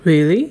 Consider a potential breakdown for a surprised question
really_surprise.wav